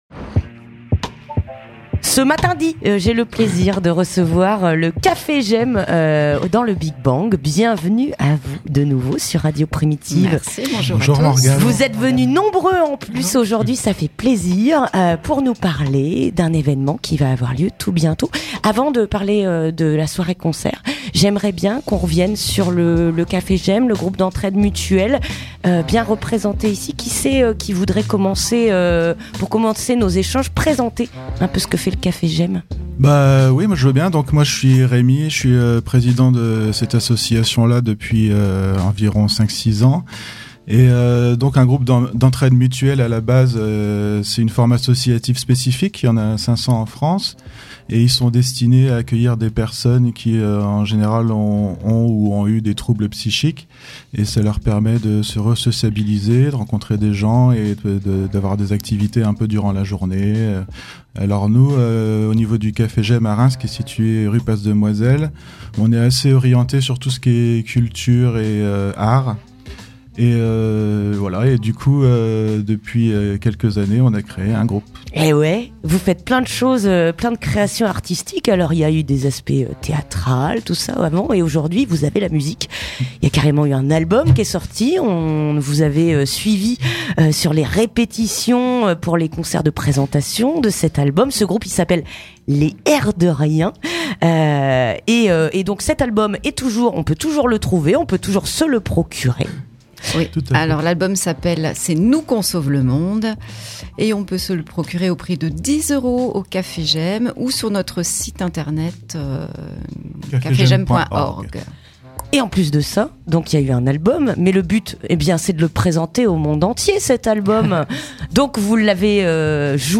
Interview du Cafégem (15:14)